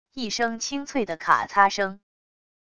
一声清脆的卡擦声wav音频